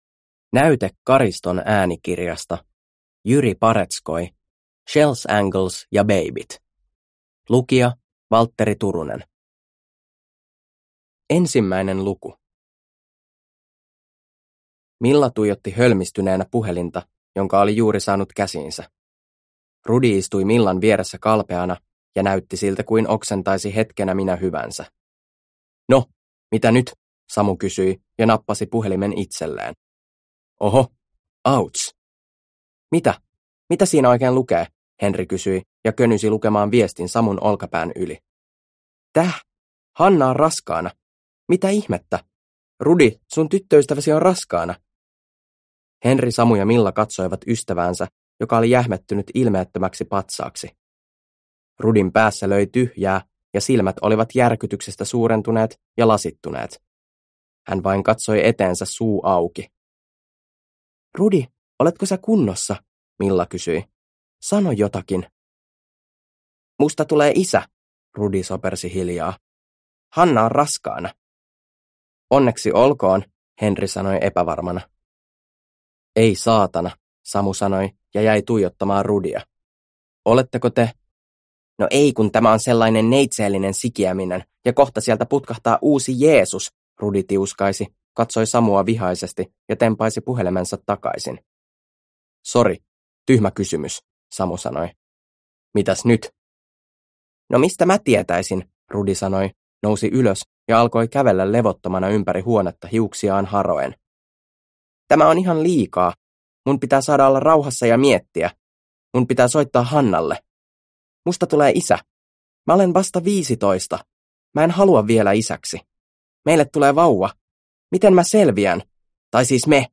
Shell's Angles ja beibit – Ljudbok – Laddas ner